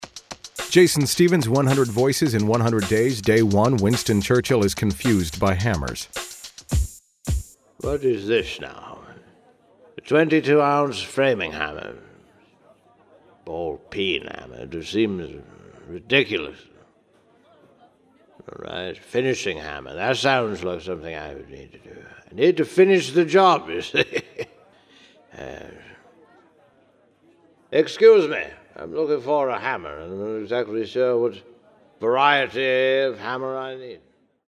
Leading off my 100 Voices in 100 Days, I wanted to go with an old favorite, so I opted for my Winston Churchill impression.
Tags: celebrity impersonations, voice match, Winston Churchill impression